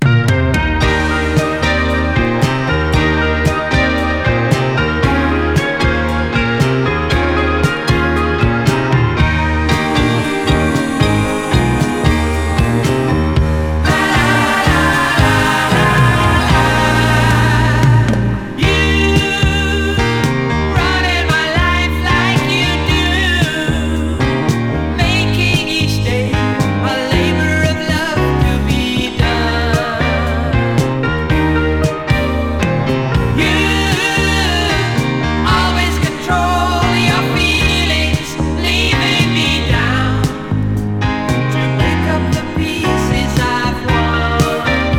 高密度なポップスを展開した宅録感満載の傑作。
Pop, Rock　Netherlands　12inchレコード　33rpm　Stereo